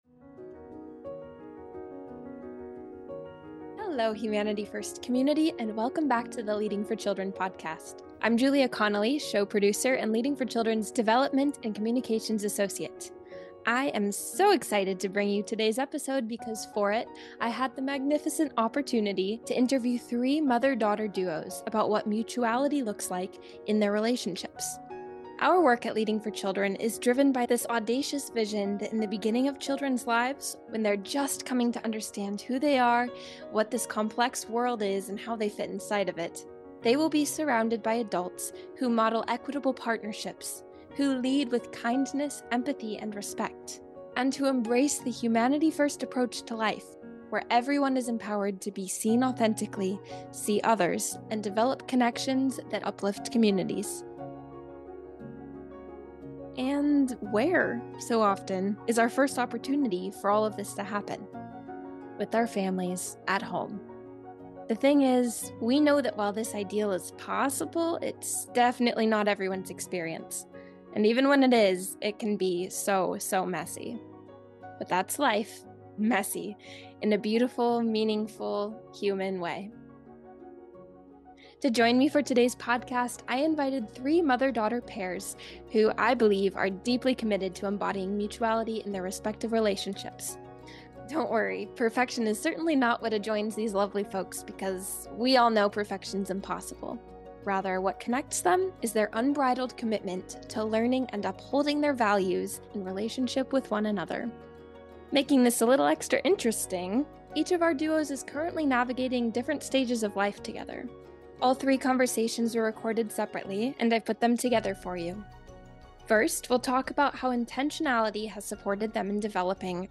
In this episode of The Leading for Children Podcast, we hear from a courageous lineup of mother-daughter duos who are deeply committed to embodying mutuality in their relationships with one another.